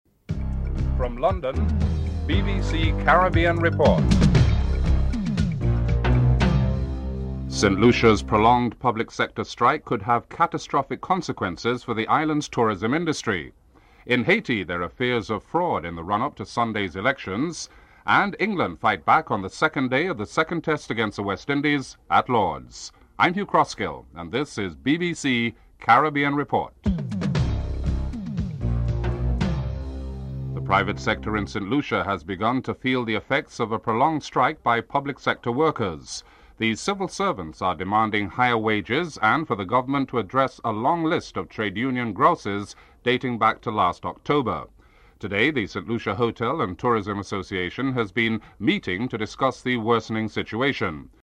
9. Recap of top stories (14:45-15:05)